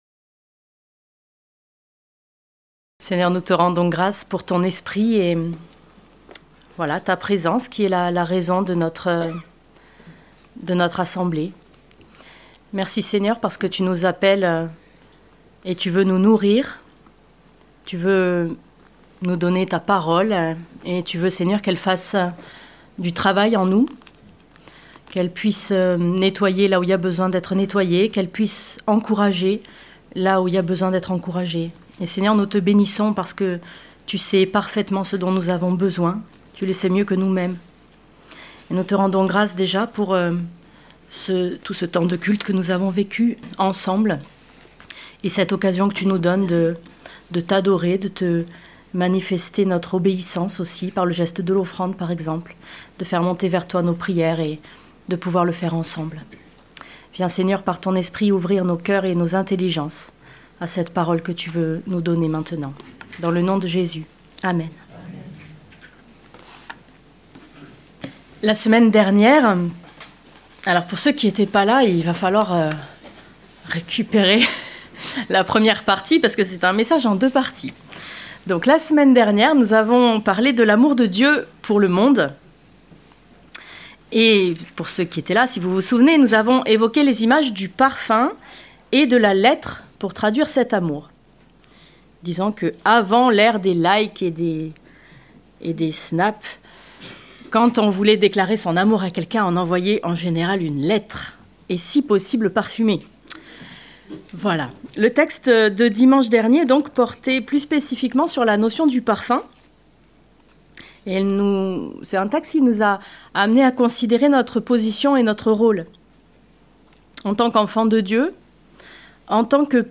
Prédication du 23 novembre 2025.